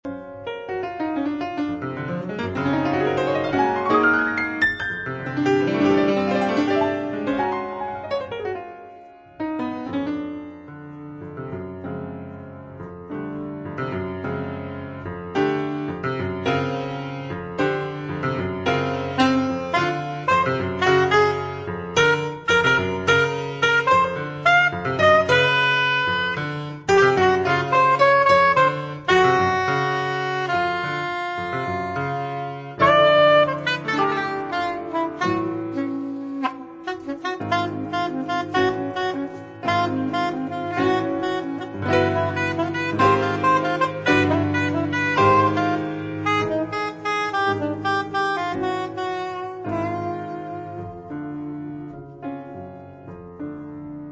piano
soprano saxophone